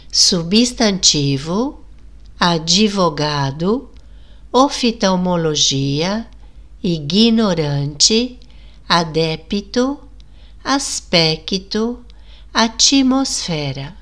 Por outro lado, acrescentamos a letra “i” em alguns encontros consonantais: